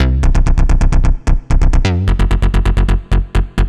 Index of /musicradar/80s-heat-samples/130bpm
AM_OB-Bass_130-C.wav